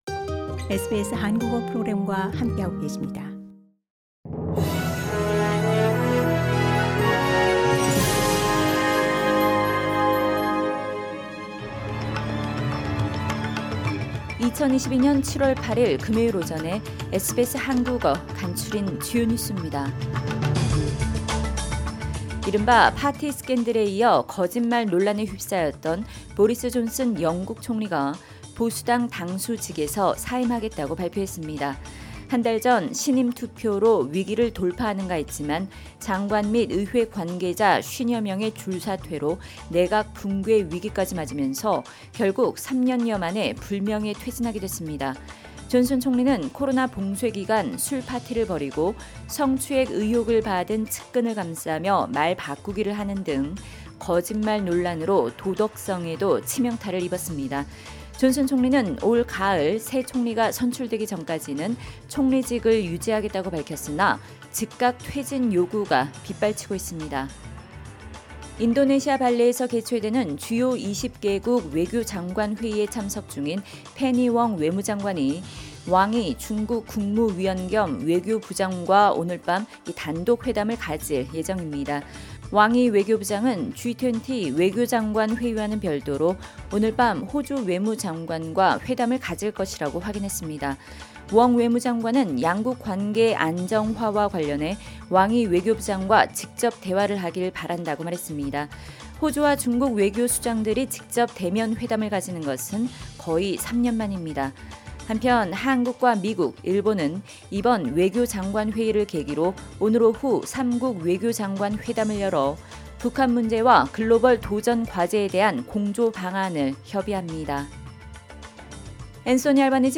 SBS 한국어 아침 뉴스: 2022년 7월 8일 금요일